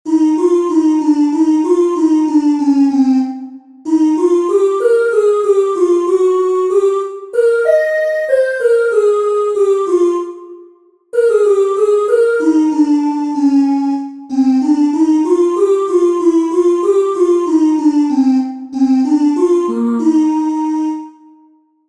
Entoación a capella
Imos practicar a entoación a capella coas seguintes melodías.
entonacion7.4capela.mp3